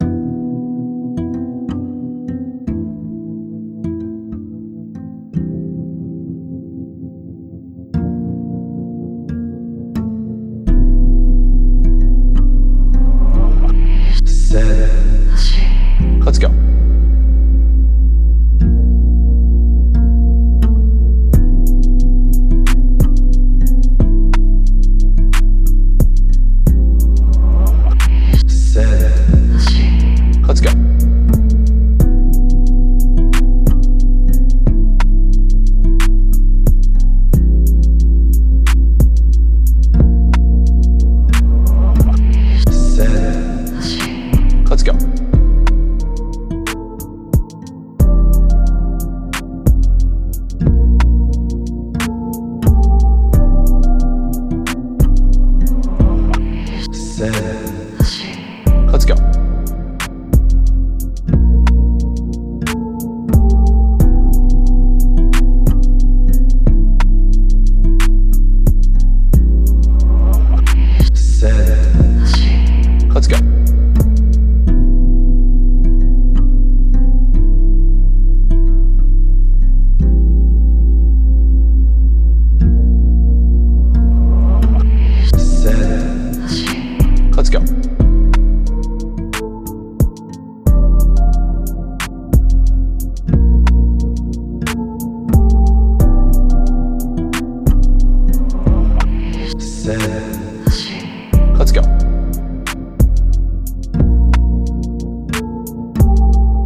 Smooth – R&B – Type Beat
Key: F
90 BPM